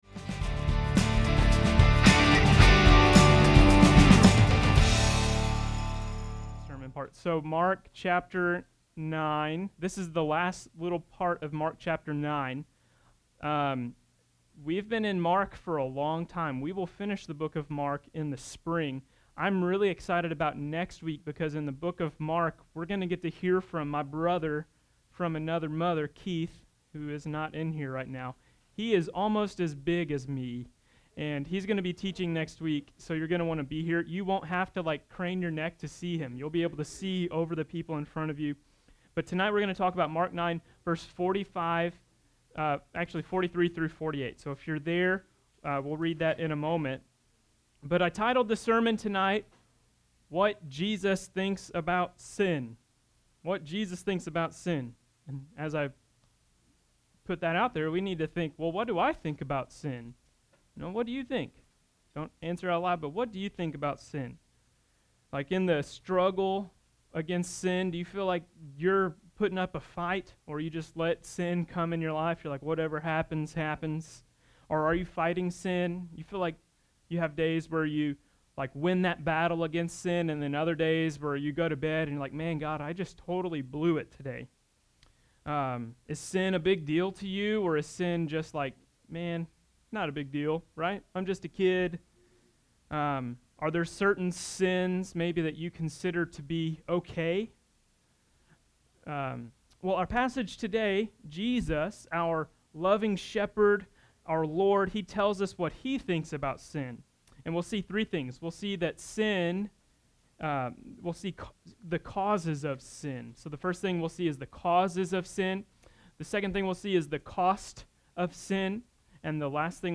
In Mark 9:43-48, Jesus tells us the Causes of Sin, the Cost of Sin, and the need to Cut off Sin. This message was originally preached to Henderson Student Ministry.